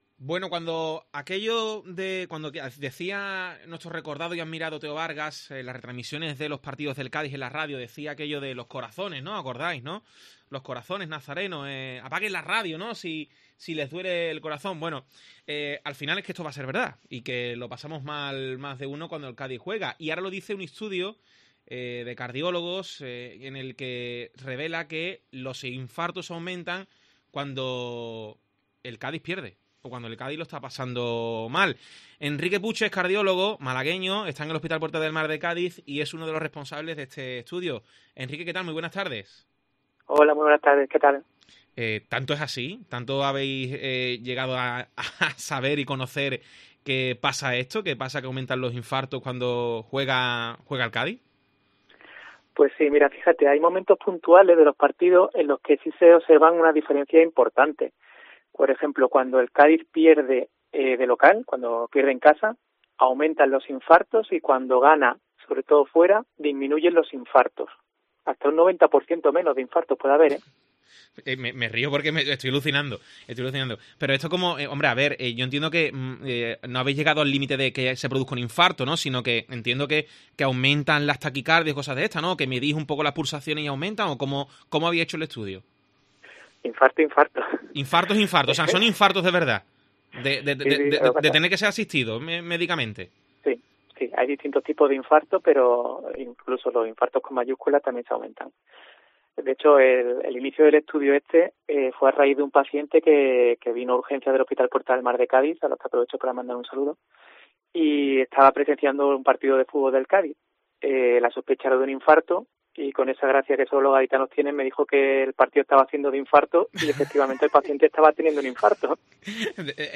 cardiólogo, explica en Deportes COPE el nivel de infartos por ver partidos del Cádiz